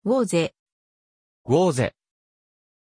Pronunciation of Waris
Japanese
pronunciation-waris-ja.mp3